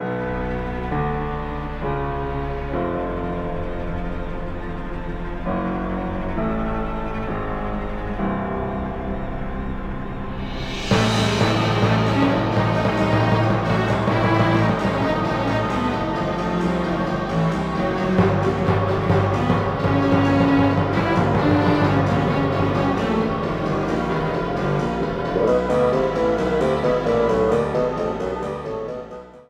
A song
Ripped from the game
trimmed to 29.5 seconds and faded out the last two seconds